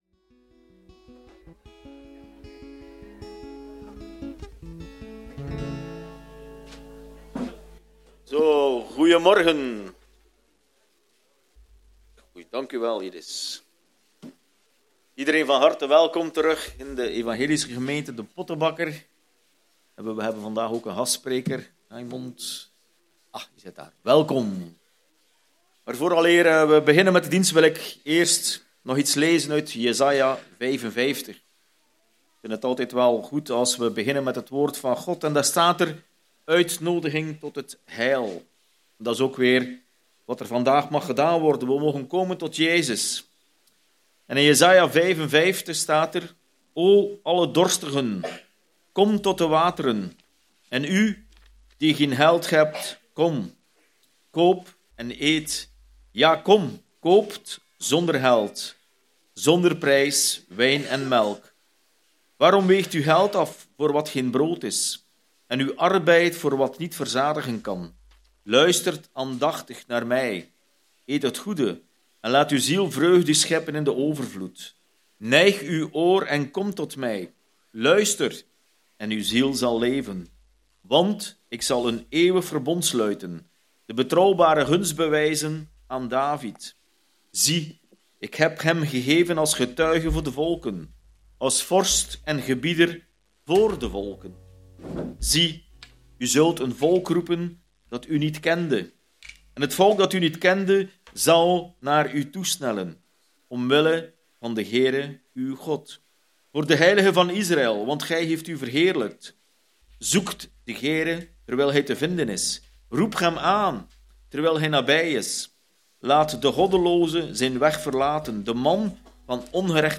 Passage: Marcus 1:14-18 Aantekeningen bij de preek